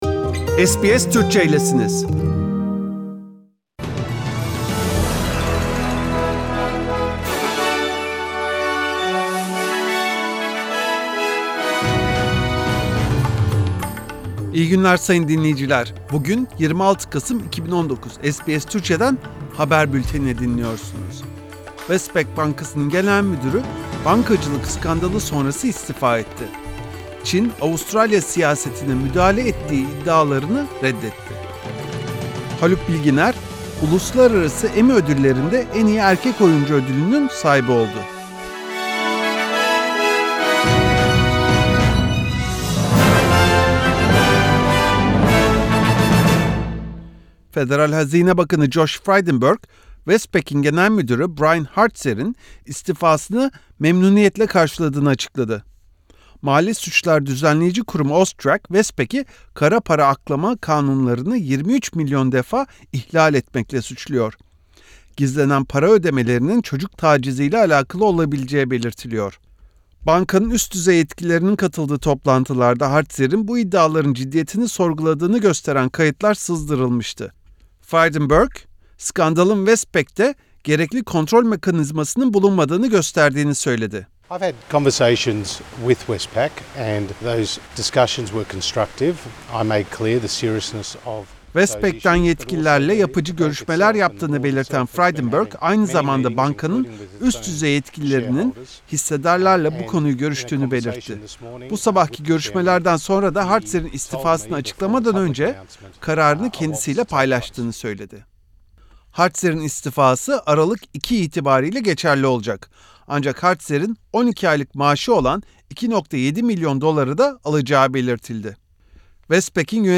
SBS Turkish News